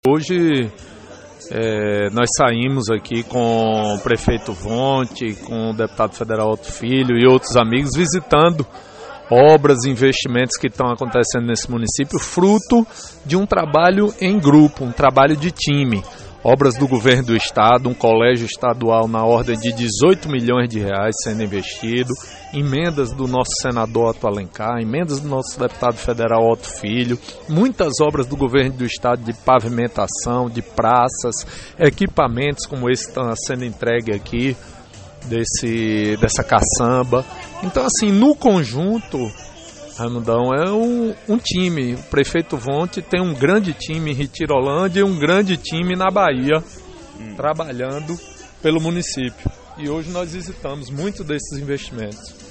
O deputado afirmou que tudo que vem sendo feito em Retirolândia é um trabalho em grupo, de time, com obras do Governo do Estado, do senador Otto Alencar, do seu mandato e do mandato de Otto Filho – OUÇA